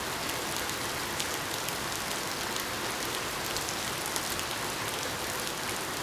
rain_medium_loop_01.wav